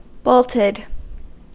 Uttal
Uttal US: IPA : [ˈboʊl.təd] Okänd accent: IPA : /ˈbəʊl.tɪd/ Ordet hittades på dessa språk: engelska Ingen översättning hittades i den valda målspråket.